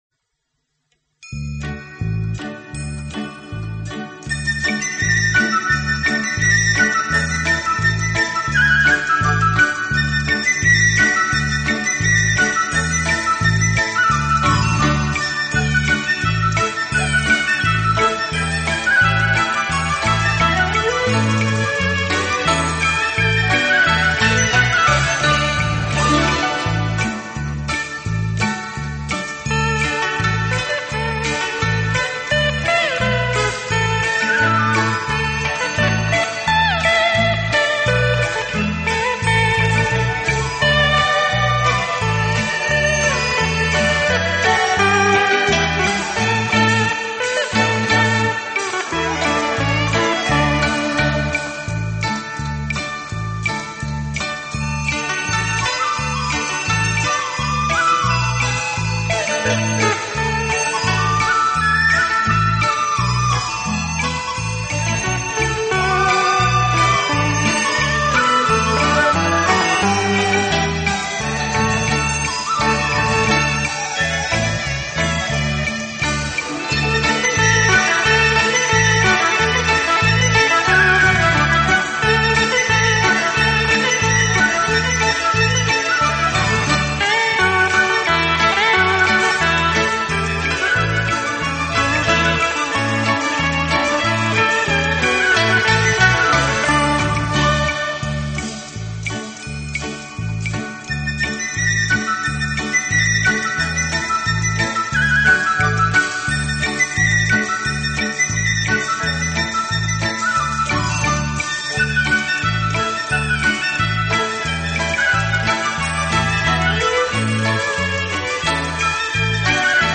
不燥，使人安静，主要以广东音乐为主，电吉他领奏
处理过程：磁带→电脑→分割→降噪→电平均衡。